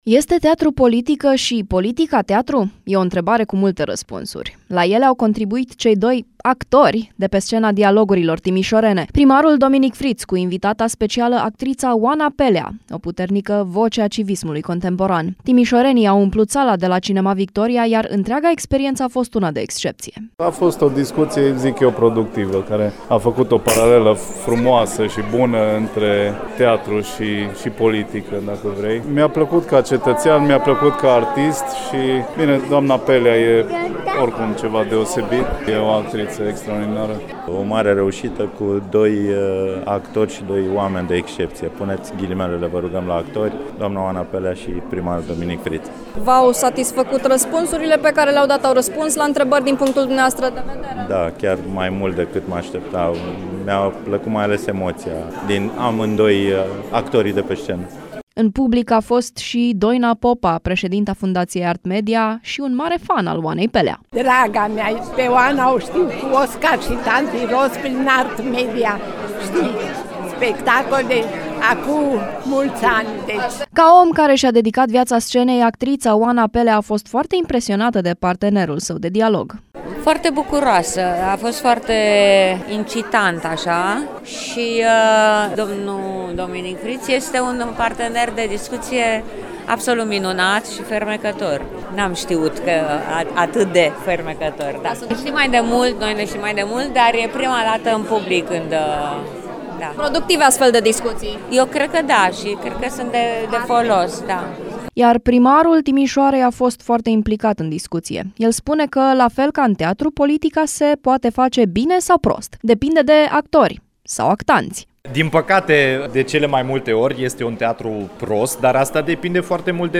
Timișorenii au umplut sala de la Cinema Victoria, iar întreaga experiență a fost una de excepție.